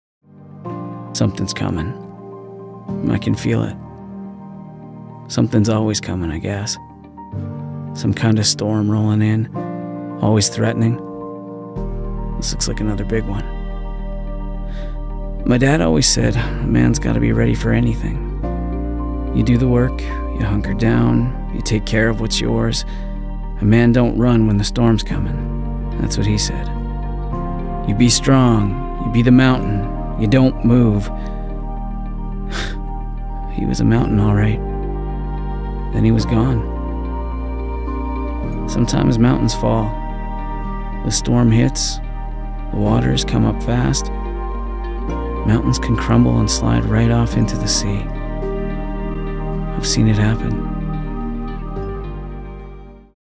Native Voice Samples
Narration
-Professional studio with sound booth
English (Canadian)